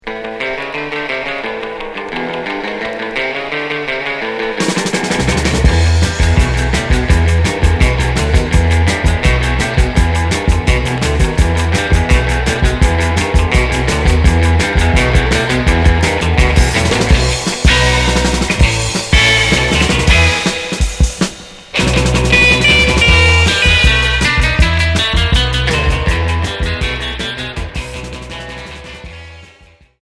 Rock 60's